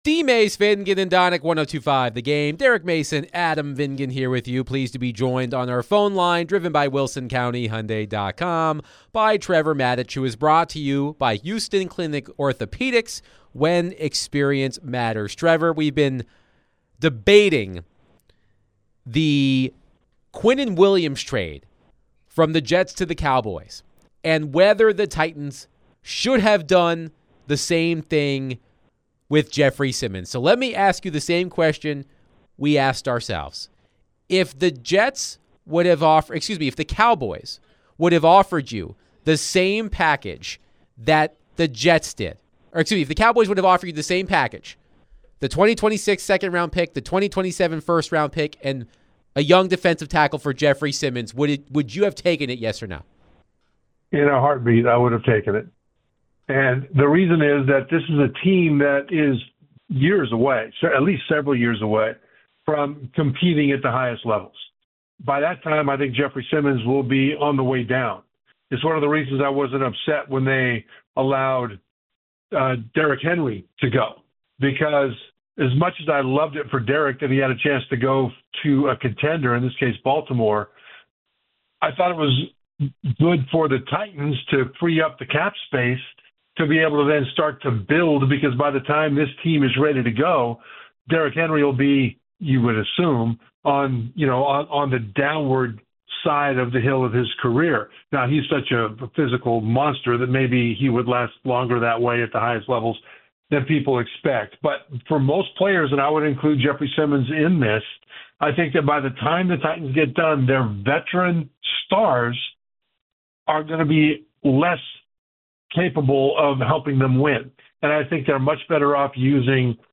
ESPN NFL and CFB Analyst Trevor Matich joined DVD to discuss all things Titans, NFL Trade Deadline, Vandy, and CFB